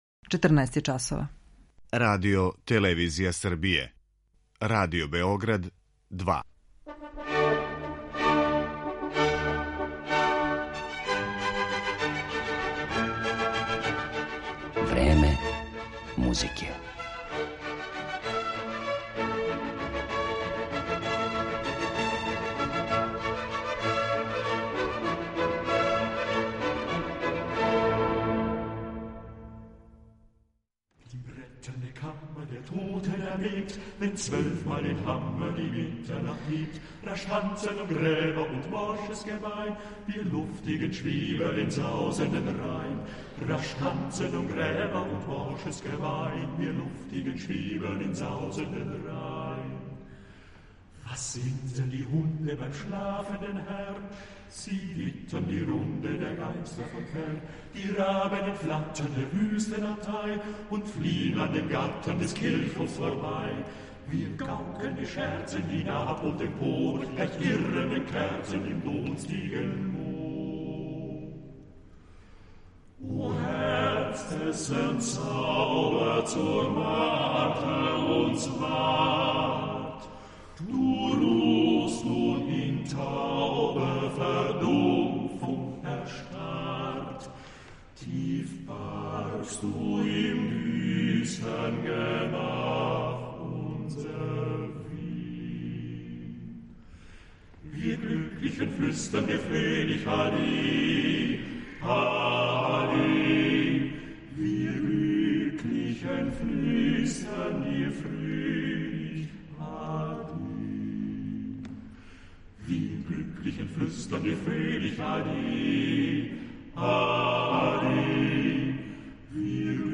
Данашња емисија посвећена је немачком мушком вокалном саставу Сингфоникер, чије је име настало ироничном игром речима певати и синфонија.
Сингфоничари су вритуозни вокални уметници способни да изведу дела веома различитог стилског и жанровског профила, а у Времену музике ћемо их слушати како певају композиције Франца Шуберта, Рихарда Штрауса, Камија Сен-Санса, Ђоакина Росинија и Орланда ди Ласа.